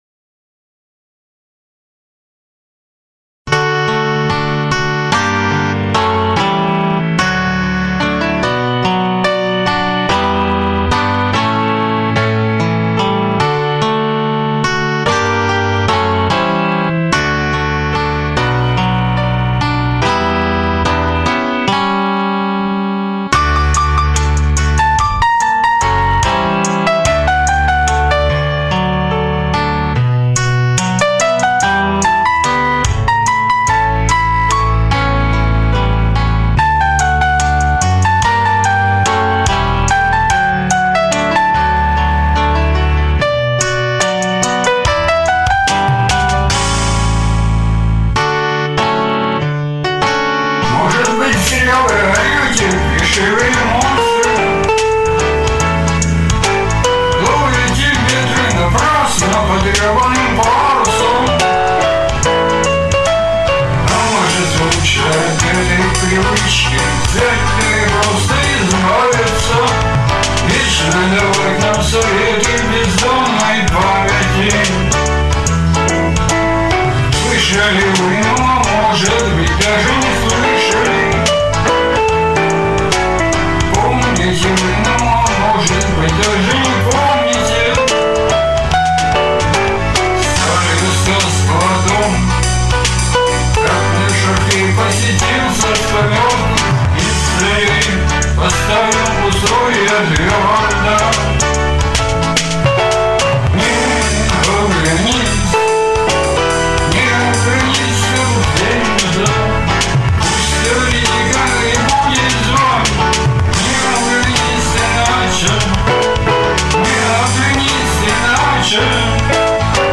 Фанк (337)